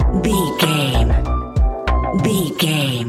Ionian/Major
B♭
chilled
laid back
Lounge
sparse
new age
chilled electronica
ambient
atmospheric
morphing